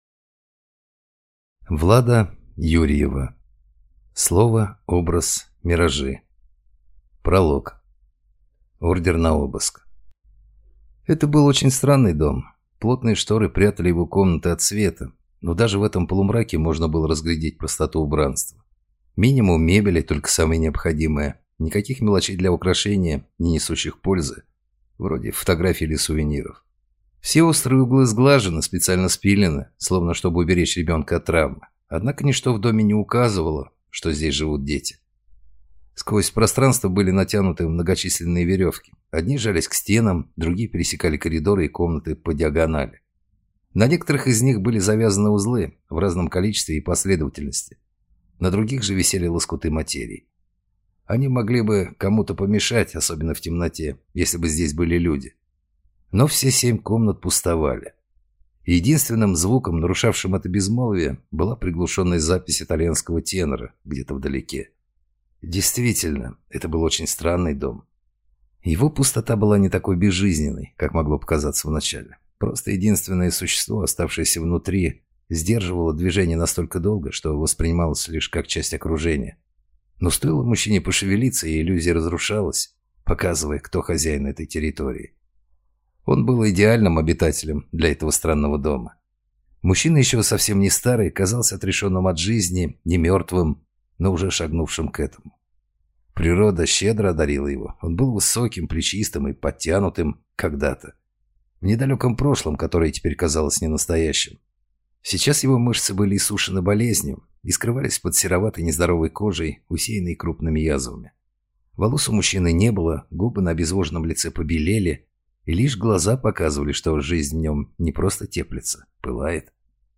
Аудиокнига Слово, образ, миражи | Библиотека аудиокниг